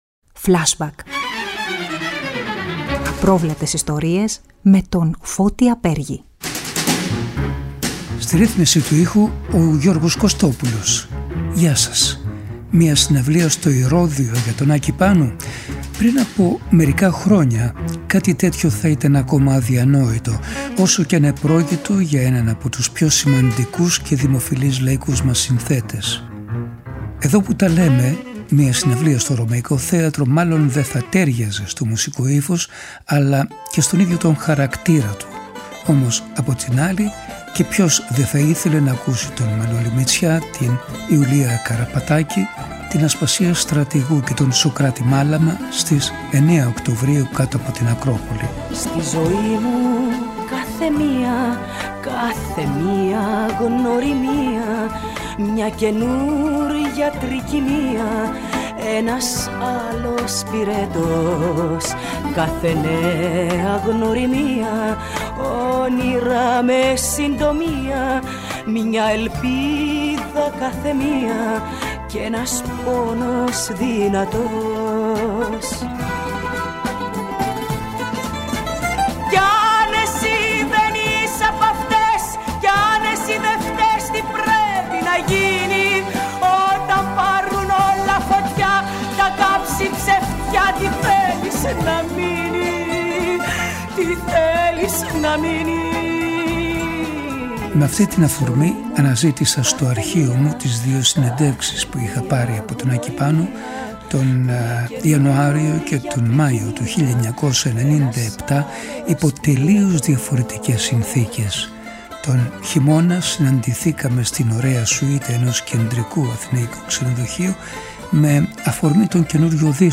Την πρώτη φορά, τον Ιανουάριο του 1997, είχαν συναντηθεί σε μια σουίτα ενός αθηναϊκού ξενοδοχείου. Τη δεύτερη, τον Μάιο του ίδιου χρόνου, στις Δικαστικές Φυλακές Κομοτηνής. Ο Άκης Πάνου είχε μιλήσει για τη ζωή του όλη.